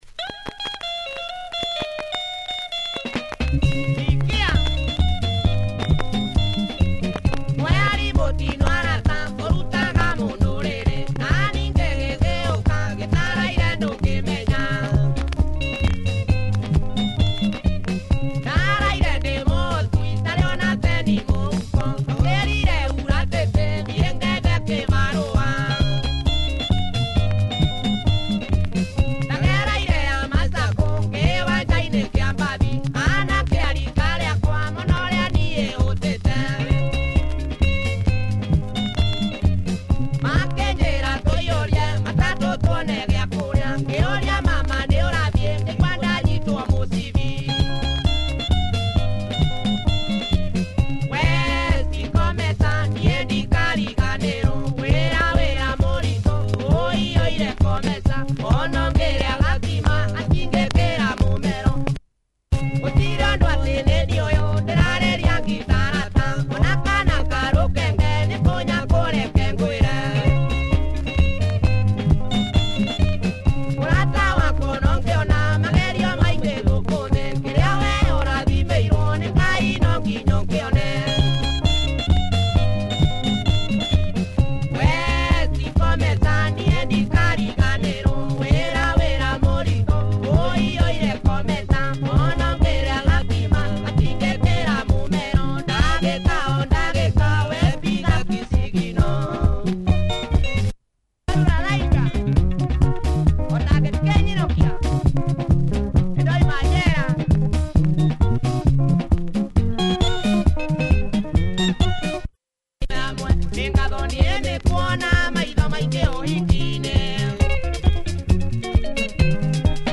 Heavy Kikuyu benga
nice backbone here, punchy tempo